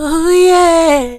SG - Vox 3.wav